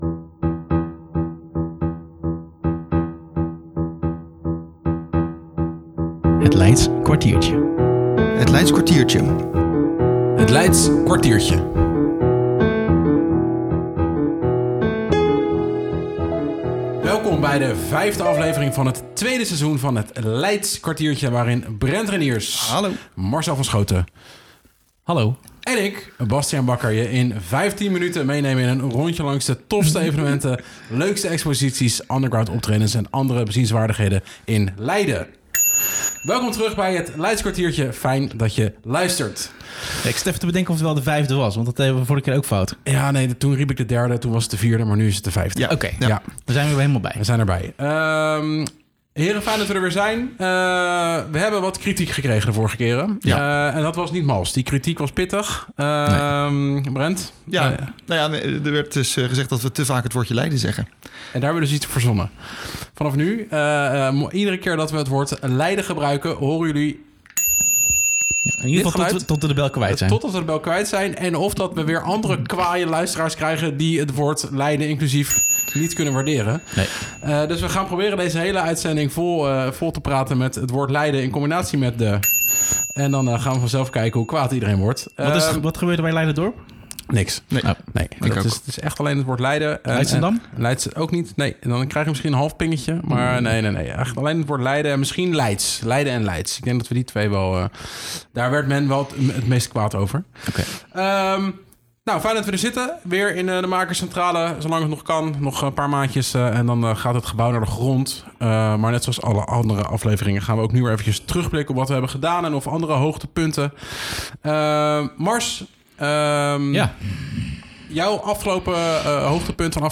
Er was namelijk wat gemopper over het gebruik van het woord Leiden -tring- om dat af te leren is daar de bel.